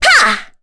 Kirze-Vox_Attack3_kr.wav